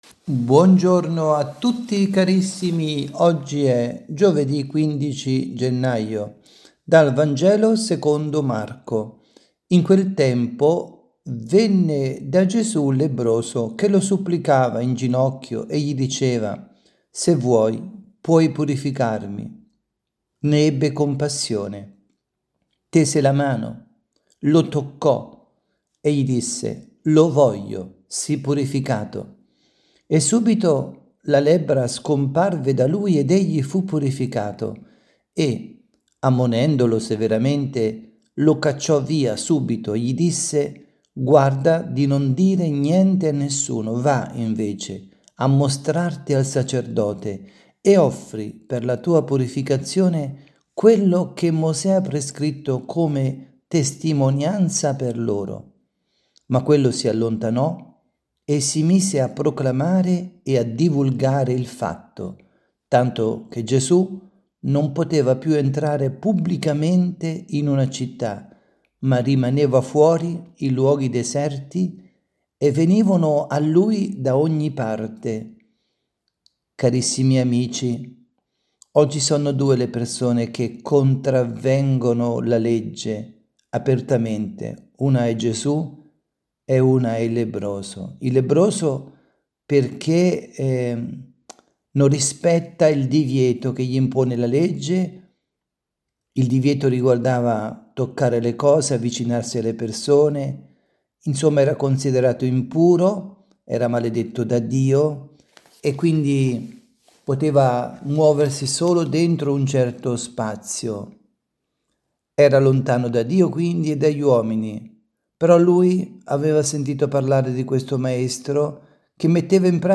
avvisi, Catechesi, Omelie, Ordinario
dalla Basilica di San Nicola – Tolentino